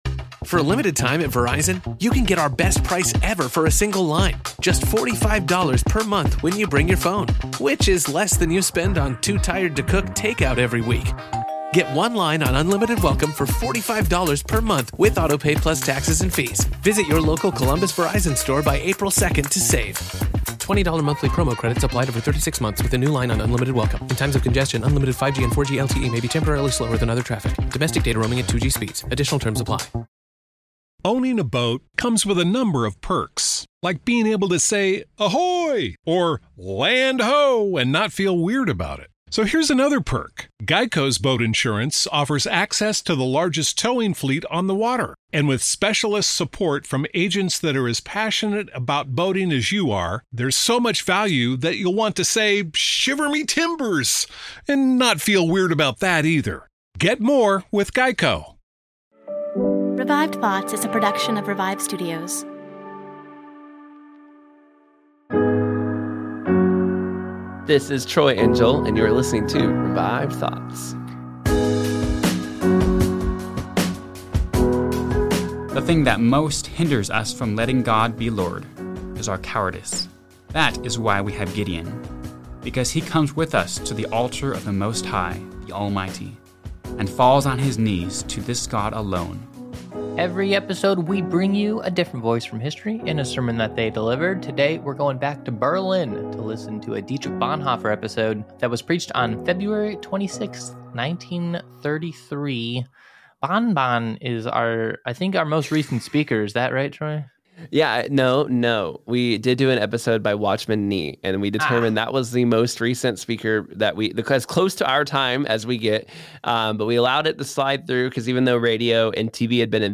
In this episode we hear a sermon he gave after Hitler was elected to be the chancellor of Germany. We also take a look at what the culture was like during the time that Bonhoeffer was preaching.